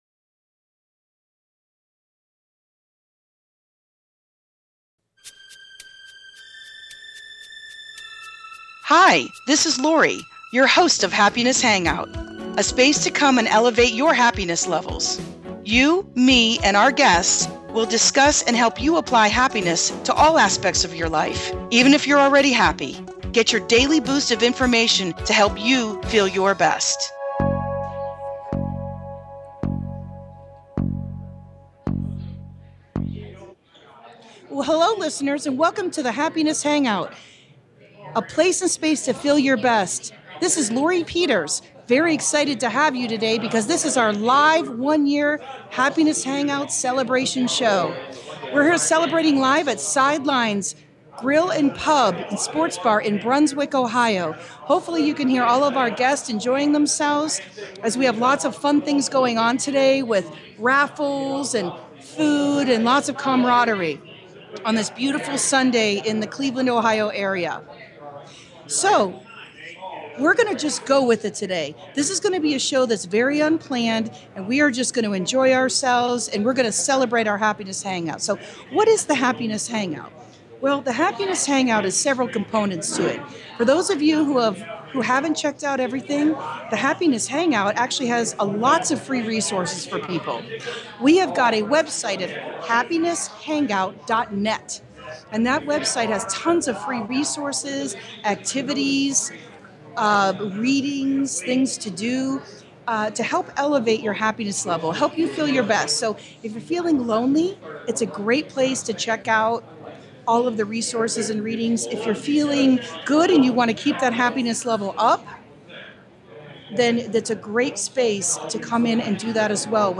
My guests are relationships experts, life coaches, therapists and much more.